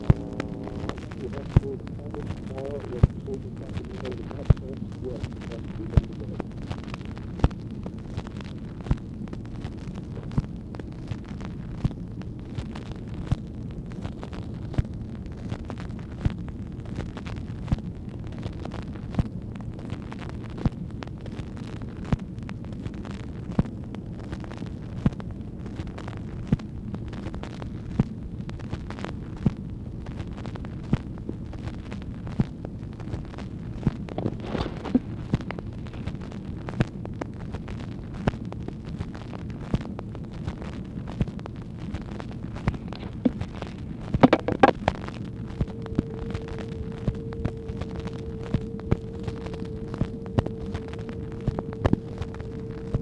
OFFICE CONVERSATION, August 10, 1964
Secret White House Tapes | Lyndon B. Johnson Presidency